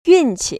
[yùn‧qi] 윈치  ▶